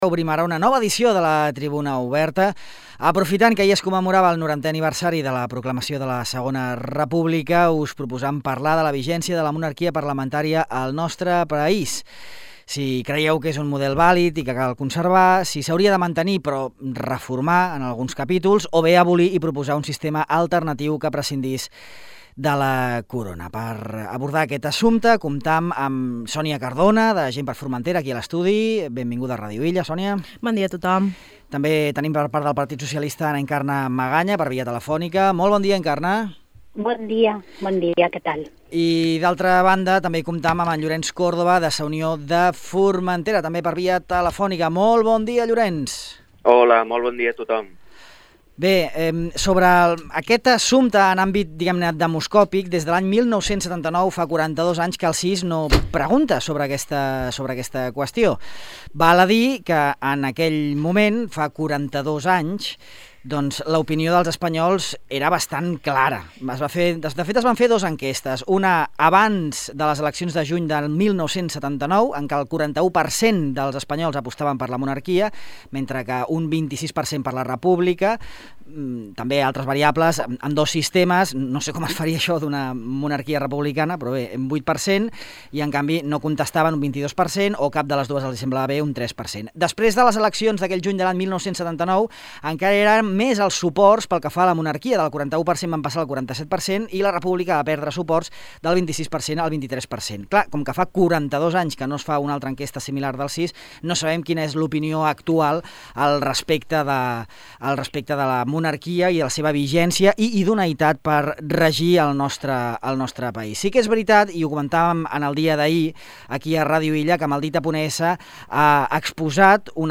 La monarquia és un sistema vàlid per a l'Espanya d'avui? A la tertúlia política
En parlam amb Encarna Magaña (PSOE), Llorenç Córdoba (Sa Unió) i Sònia Cardona (GxF).